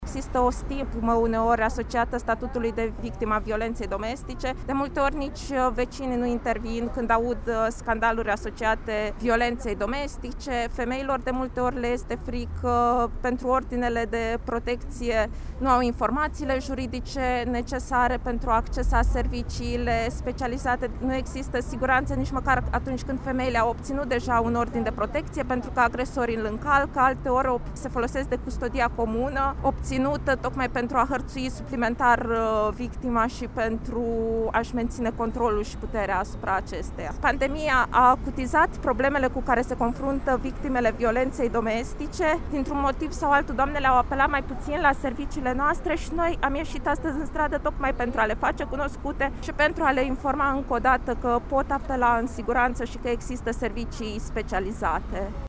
Asistenta-sociala.mp3